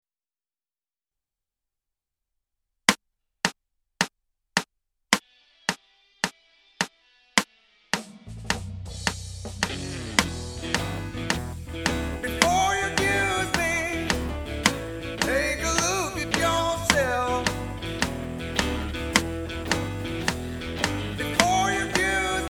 Behringer X32 mitschnitt
Wenn ich einen Mitschnitt von Logic auf den internen usb Stick vom Behringer Recordern will sind manche Kanäle so weit weg als wenn es nur den Effekt Anteil aufnimmt.